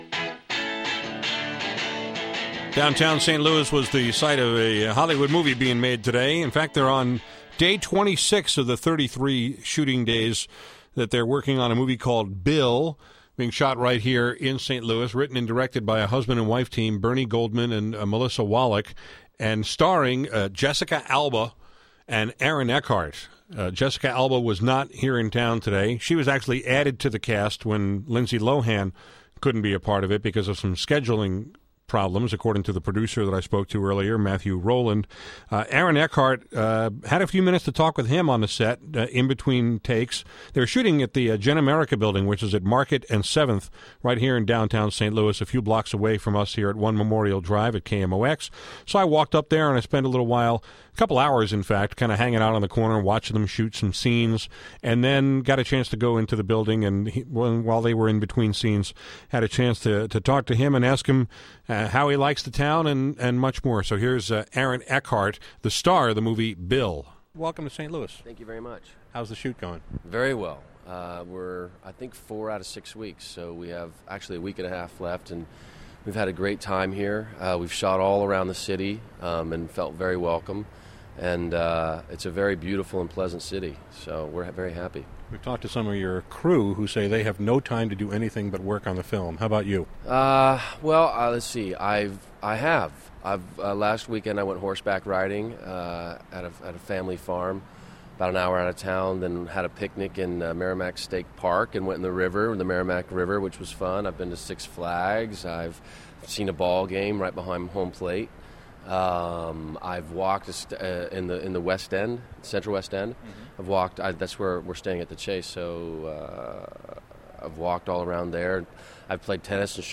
I spent a couple of hours this morning on the set of “Bill,” a movie that’s being shot here in St. Louis this summer, starring Aaron Eckhart, a very good actor who has been in “Thank You For Smoking,” “In The Company of Men,” “Erin Brockovich,” and more.
During a break between scenes, I talked with Eckhart for a few minutes about what it’s like to make a movie here, how changing the leading lady from Lindsay Lohan to Jessica Alba affected the movie, and whether our notorious summer heat and humidity were getting in the way of his performance.